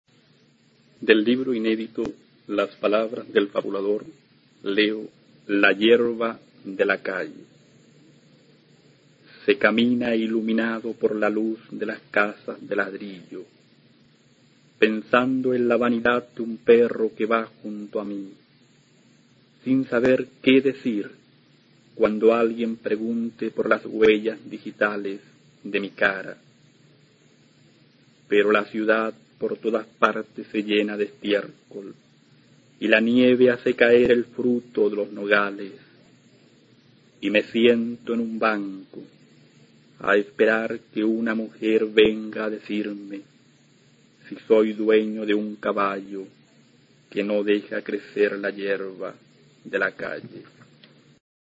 A continuación se puede escuchar a Jaime Quezada, autor chileno de la Generación del 60, recitando su poema La hierba de la calle, del libro "Las palabras del fabulador" (1968).
Poema